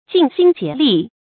注音：ㄐㄧㄣˋ ㄒㄧㄣ ㄐㄧㄝ ˊ ㄌㄧˋ
盡心竭力的讀法